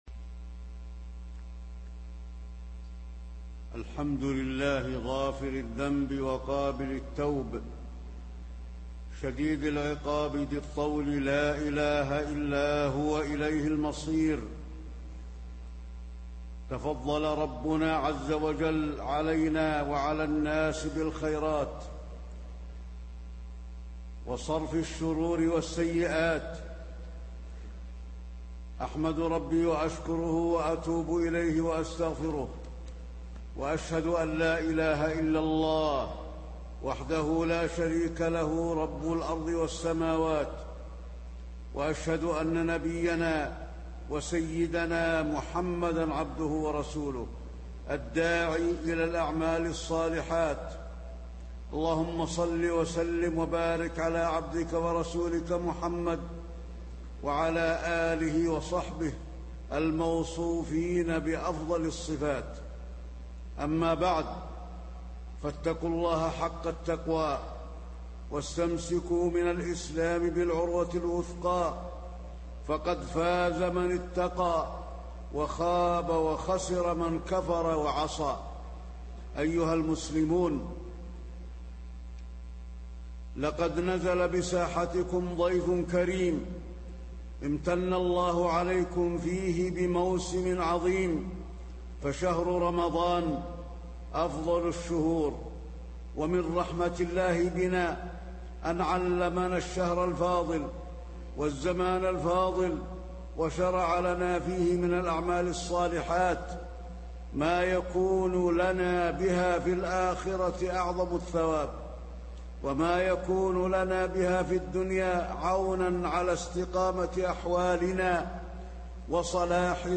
تاريخ النشر ٣ رمضان ١٤٣٤ هـ المكان: المسجد النبوي الشيخ: فضيلة الشيخ د. علي بن عبدالرحمن الحذيفي فضيلة الشيخ د. علي بن عبدالرحمن الحذيفي رمضان النفحات والبركات